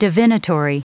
Prononciation du mot divinatory en anglais (fichier audio)
Prononciation du mot : divinatory